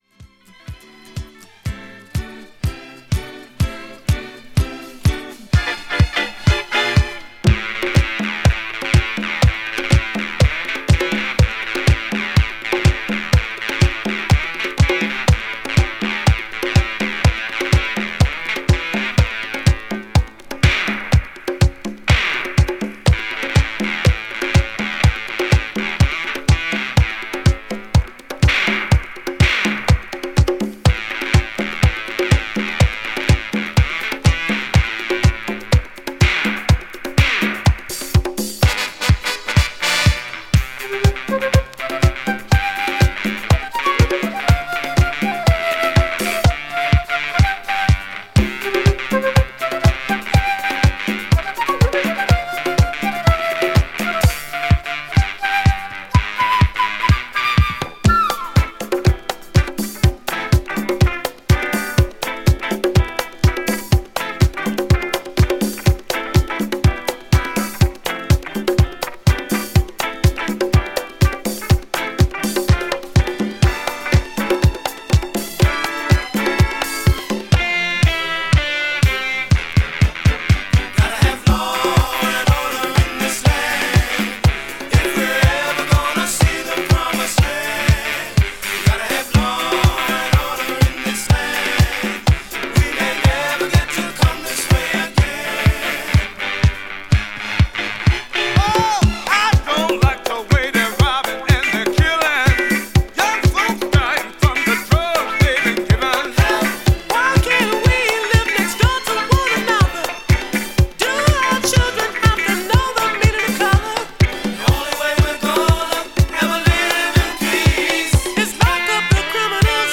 DANCE
アメリカ盤よりもスマートなマスタリングで人気のカナダプレスです！！！